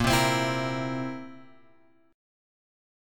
A# Suspended 2nd Flat 5th